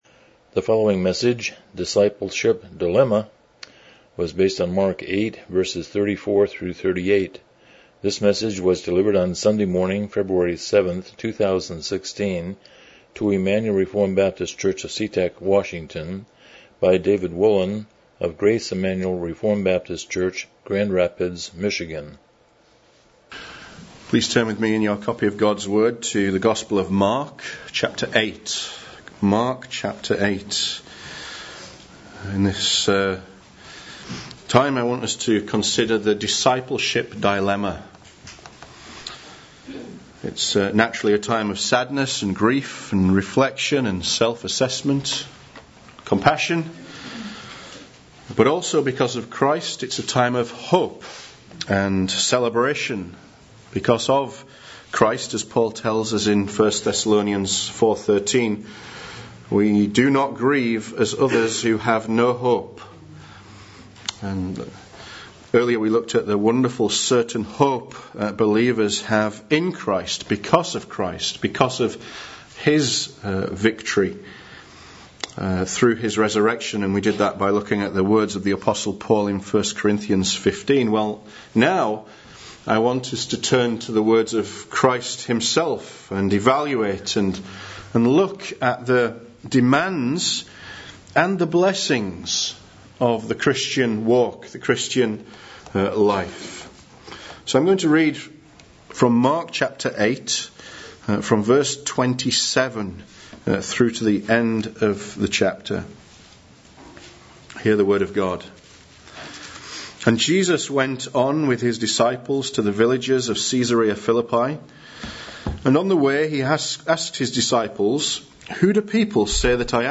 Passage: Mark 8:34-38 Service Type: Morning Worship « Because He Lives I Can Face Tomorrow How the Glory of God Impacts Man